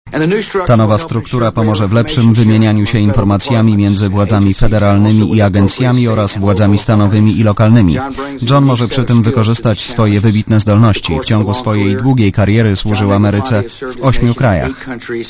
Mówi prezydent George Bush